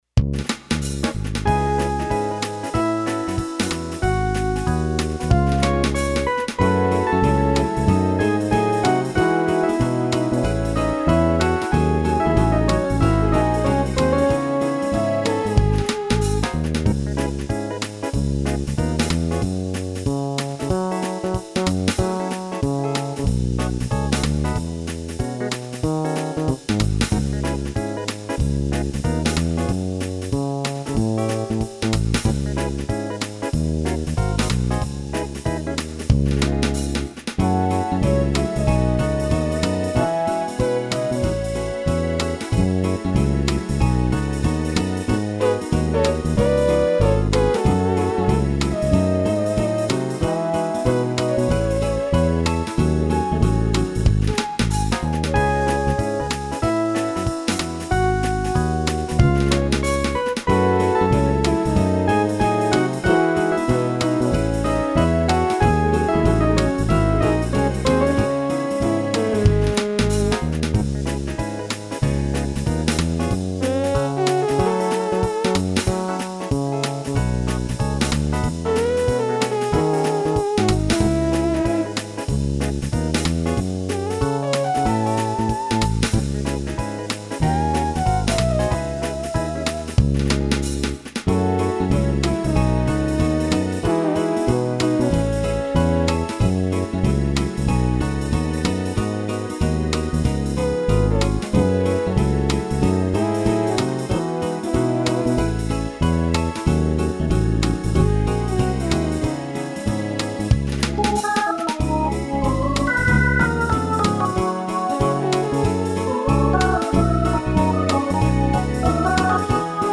Пойте караоке
минусовка версия 4543